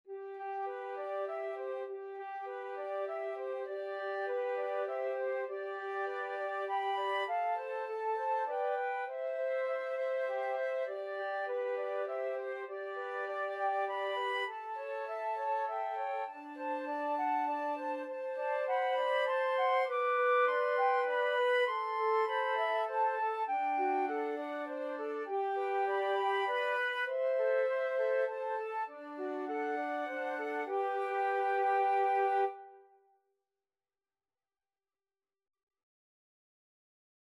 Free Sheet music for Flute Trio
Flute 1Flute 2Flute 3
17th-century English folk song.
G major (Sounding Pitch) (View more G major Music for Flute Trio )
3/4 (View more 3/4 Music)
Moderato